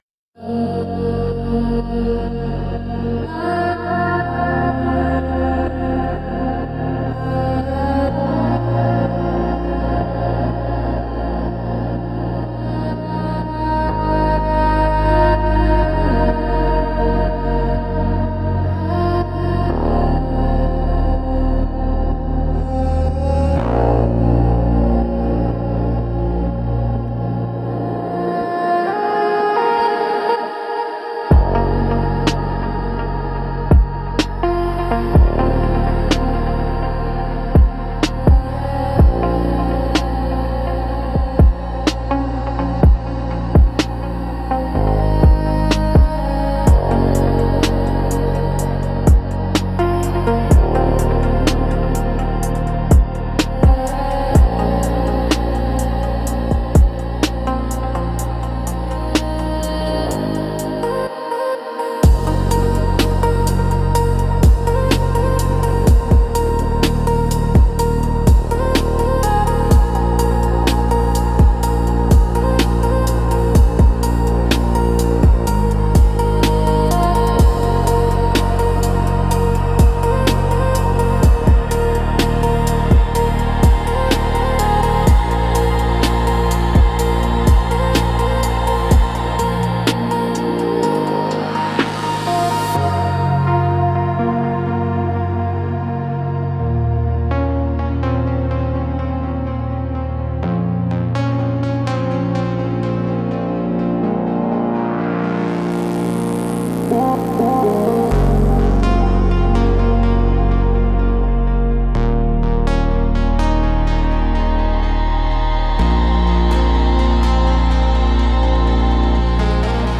Instrumental - Last Transmission 3.19